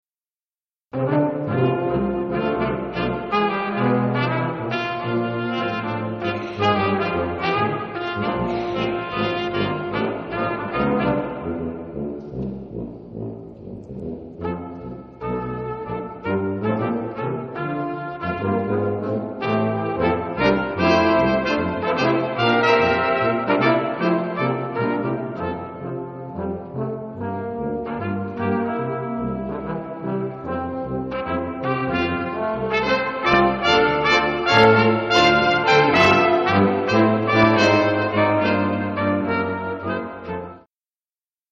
St. Rose Concert Series 2006
Polished Brass
St. Rose Catholic Church